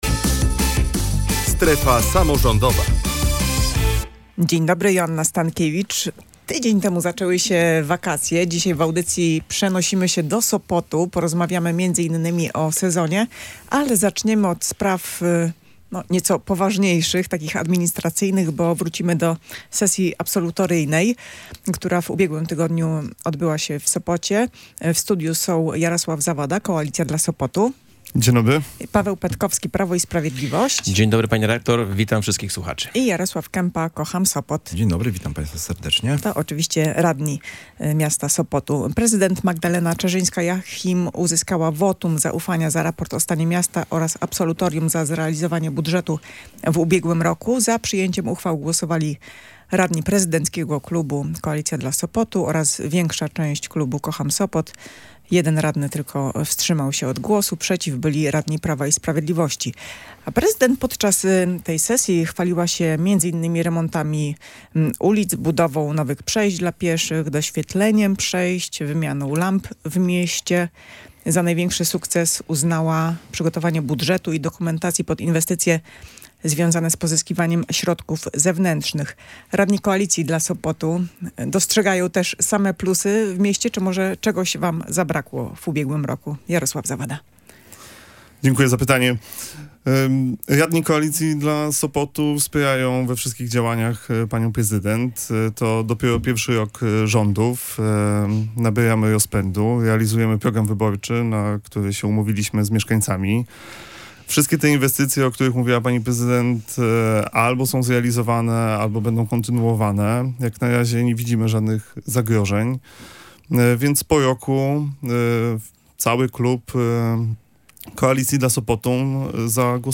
Jaki był ostatni rok dla Sopotu? Posłuchaj dyskusji radnych